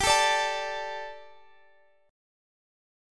Listen to Gsus2 strummed